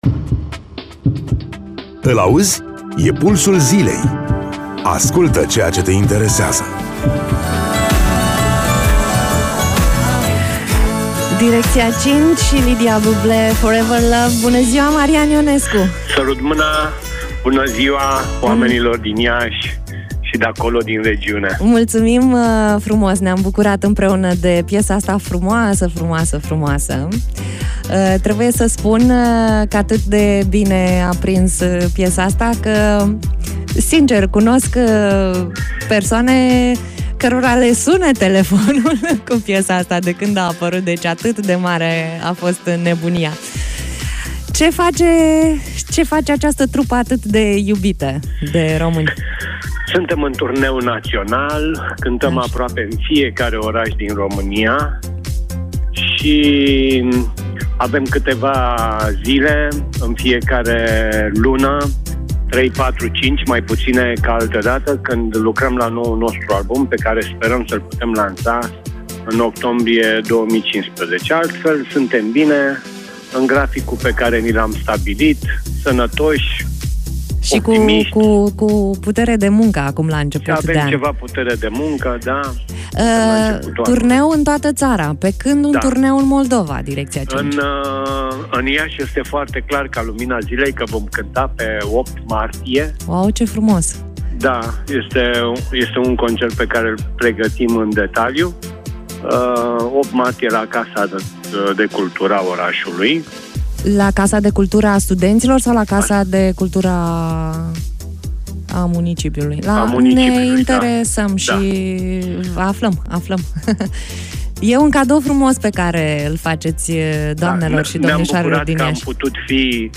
Interviu-Marian-Ionescu-Directia-5.mp3